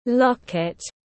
Cái mặt dây chuyền lồng ảnh tiếng anh gọi là locket, phiên âm tiếng anh đọc là /ˈlɒk.ɪt/.